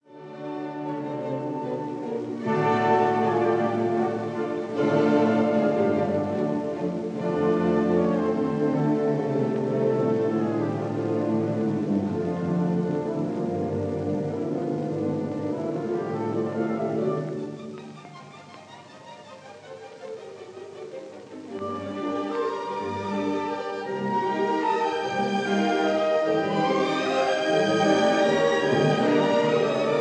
Allegretto grazioso – Molto vivace